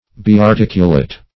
Search Result for " biarticulate" : The Collaborative International Dictionary of English v.0.48: Biarticulate \Bi`ar*tic"u*late\, a. [Pref. bi- + articulate.]